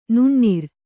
♪ nuṇṇir